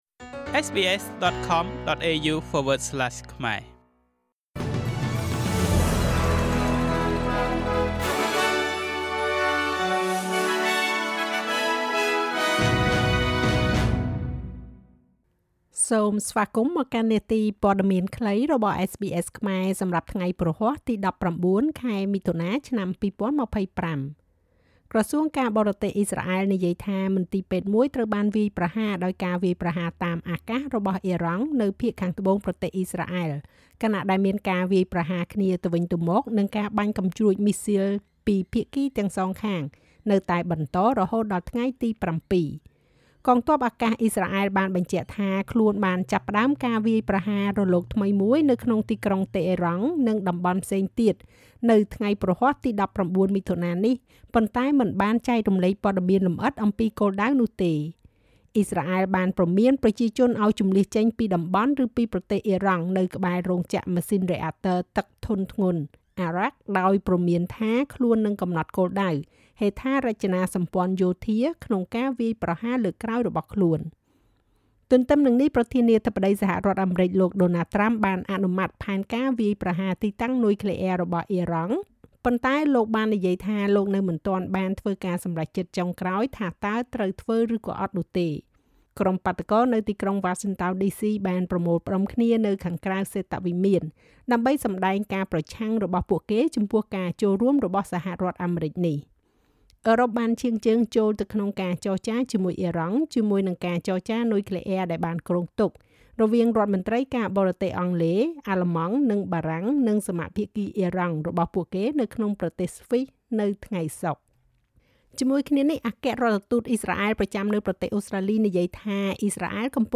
នាទីព័ត៌មានខ្លីរបស់SBSខ្មែរ សម្រាប់ថ្ងៃព្រហស្បតិ៍ ទី១៩ ខែមិថុនា ឆ្នាំ២០២៥